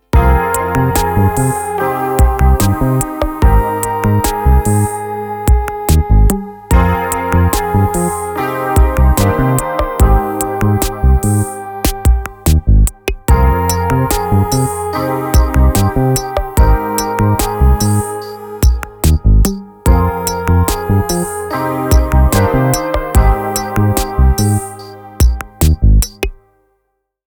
Classic CR-78 grooves with authentic tones. Bonus synth/piano sounds & Ableton Live rack included.
These sounds are directly sourced from the original machines, ensuring every beat carries their unmistakable character and warmth.
CR-78_beatpack_demo.mp3